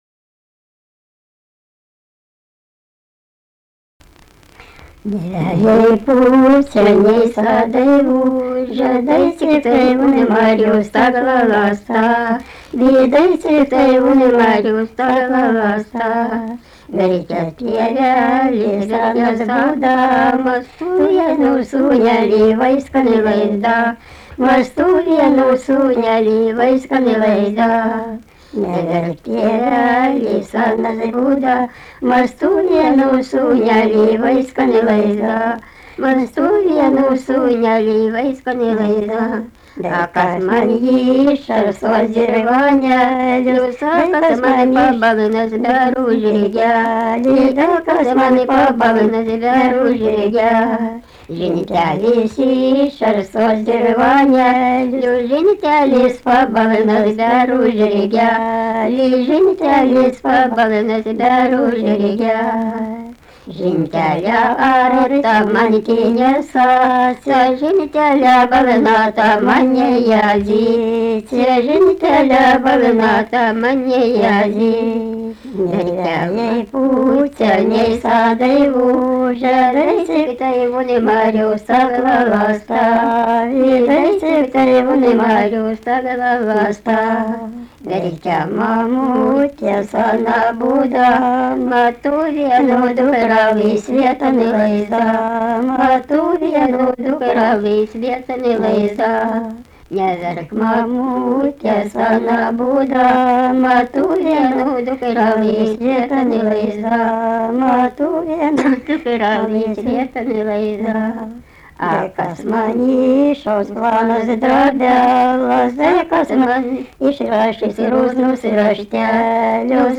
daina
Gilūtos, Gilūtų k.
vokalinis